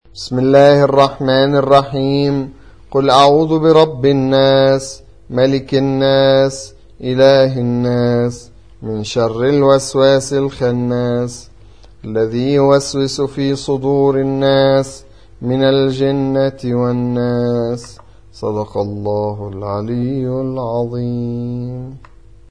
سورة الناس / القارئ